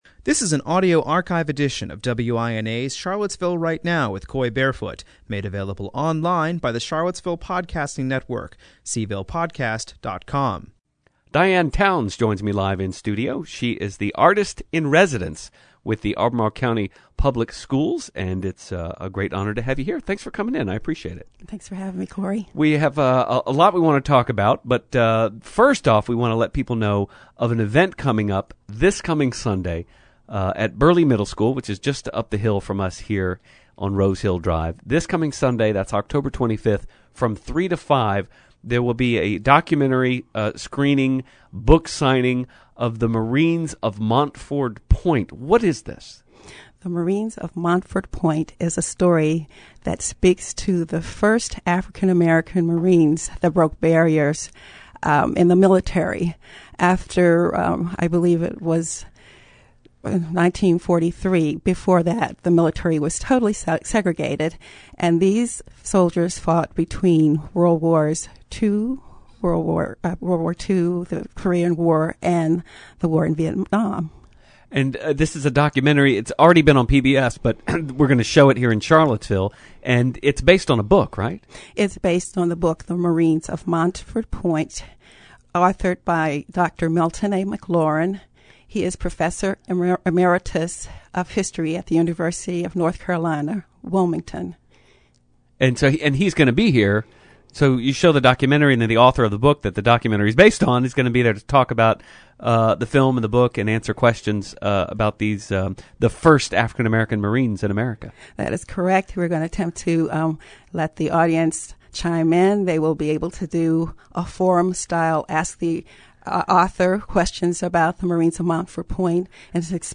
Interviews , WINA